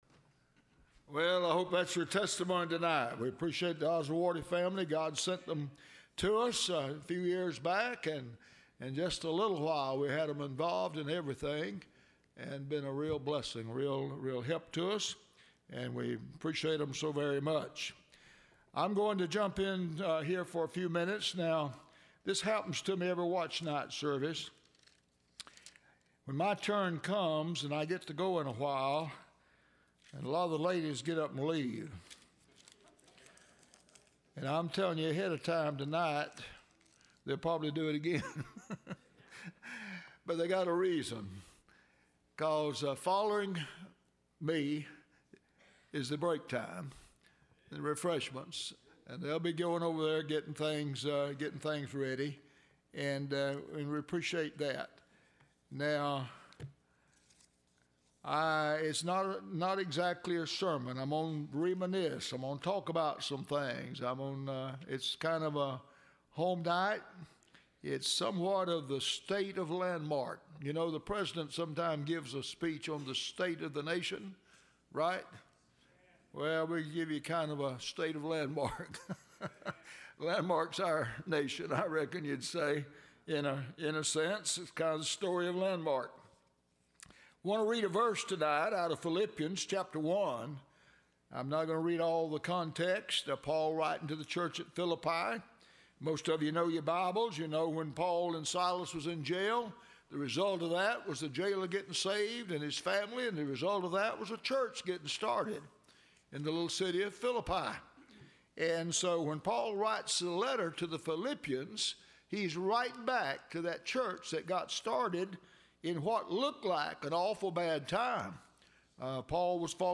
Watchnight Service 2017-18 – Landmark Baptist Church
Service Type: Sunday Evening